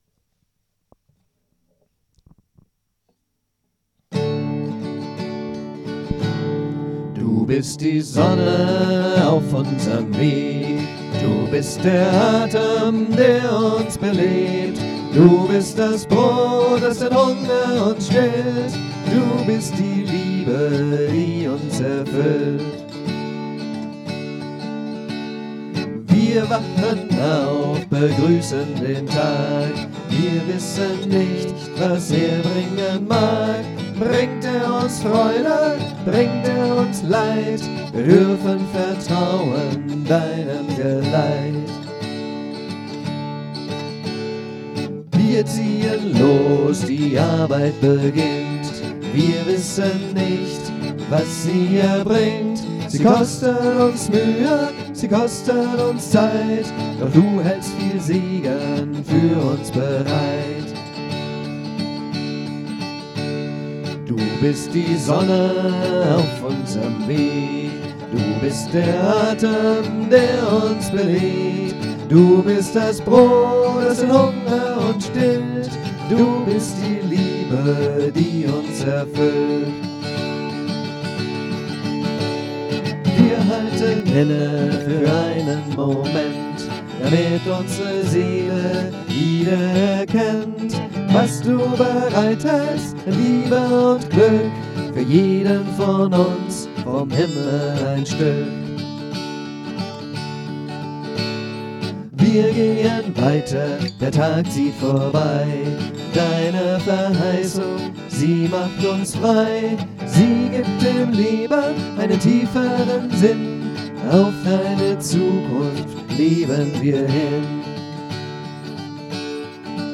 3/4 Takt, D-Dur; recht schwungvoll; Noten verfügbar